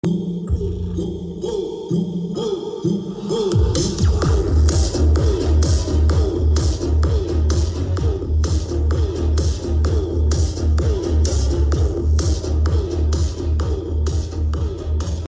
MAX Three-way subwoofer Rated 200 watts of high power